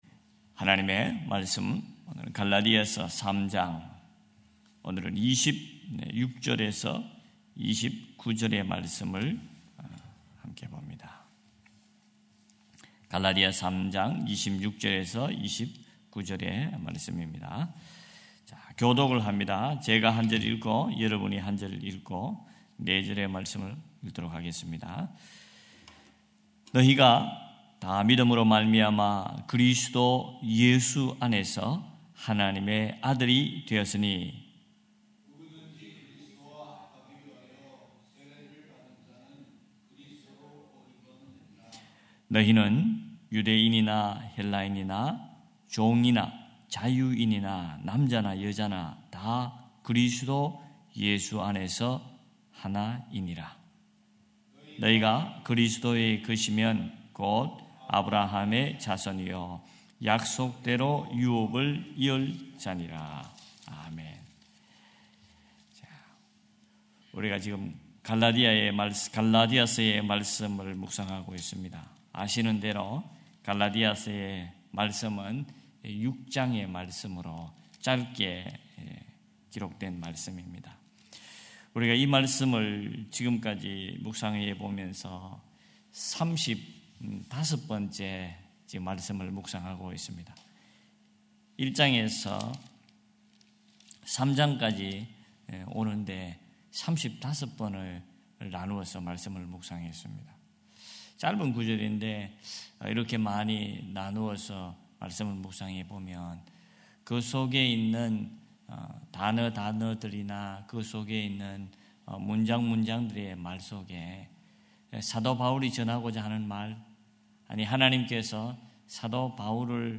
July19 2025 새벽예배 갈라디아서 3장 26-29절